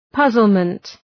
{‘pʌzəlmənt}
puzzlement.mp3